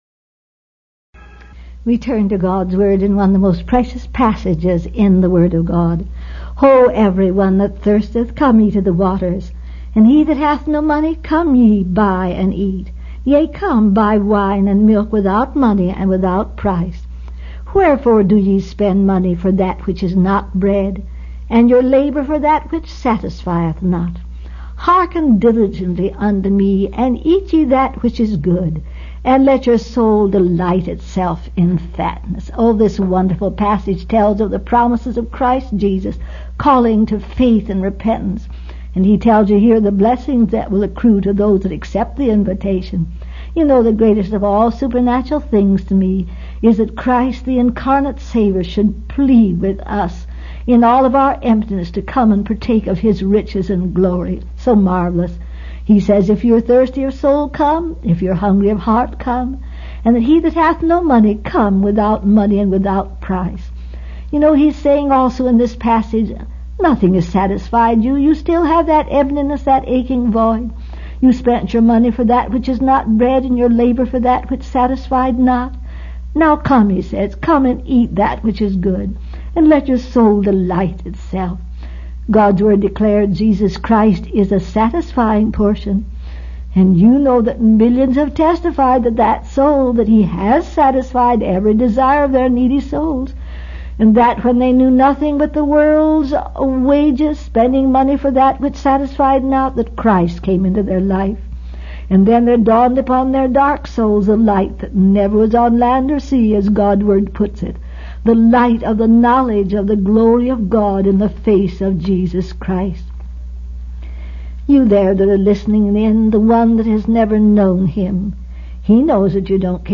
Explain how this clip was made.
This was a transcript of a Meditation Moments #23 broadcast.